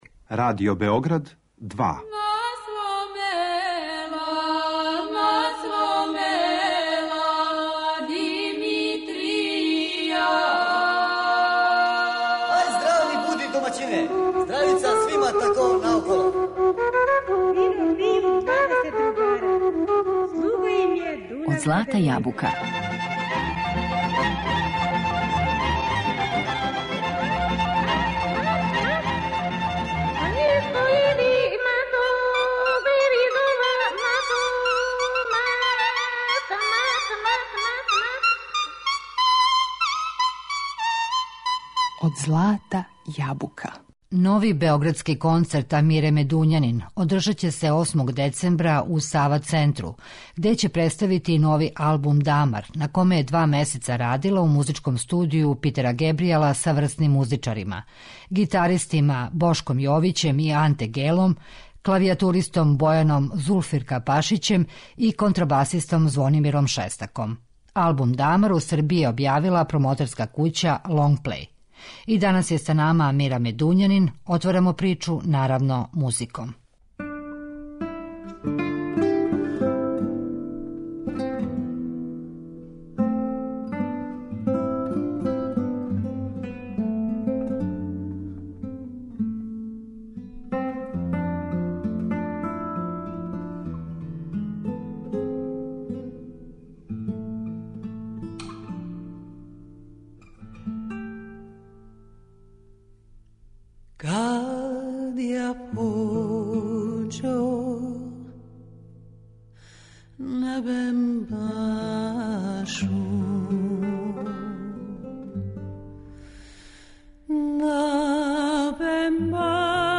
У две емисије 'Од злата јабука', гост је Амира Медуњанин, која важи за једну од најбољих извођача севдалинки.